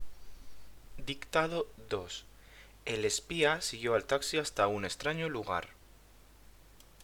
Dictado